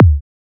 edm-kick-12.wav